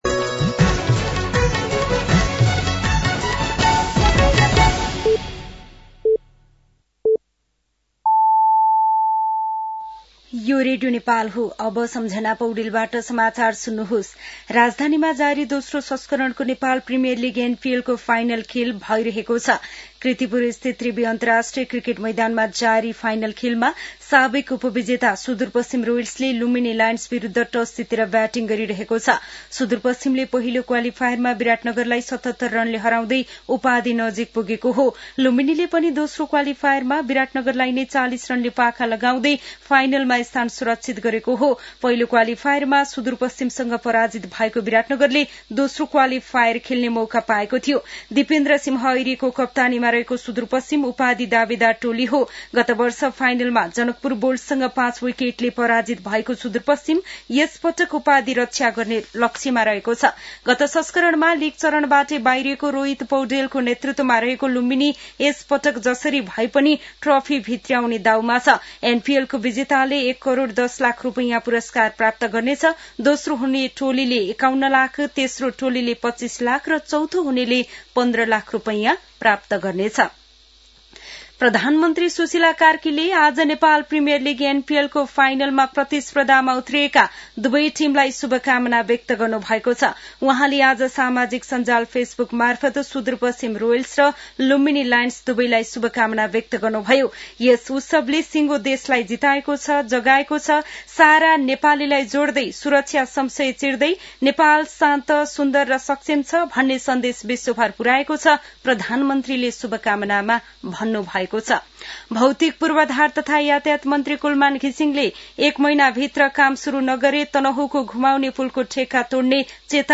साँझ ५ बजेको नेपाली समाचार : २७ मंसिर , २०८२
5.-pm-nepali-news-1-2.mp3